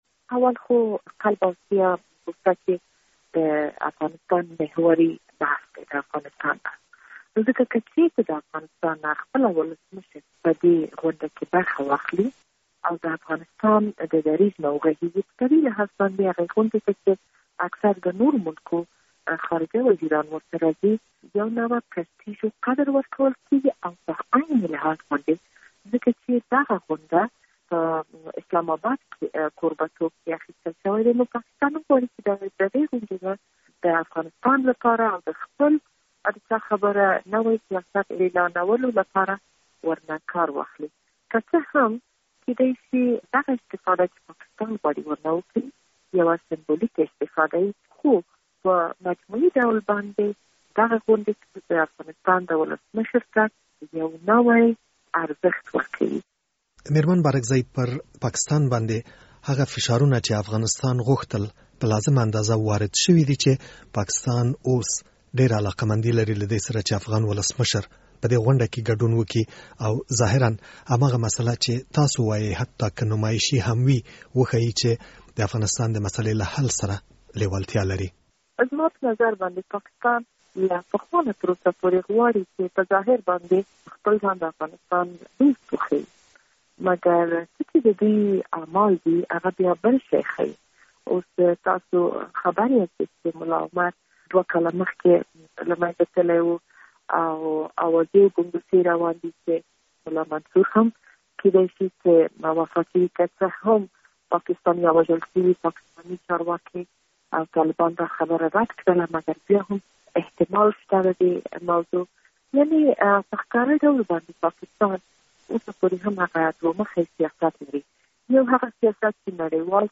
مرکه
له شکريي بارکزی سره مرکه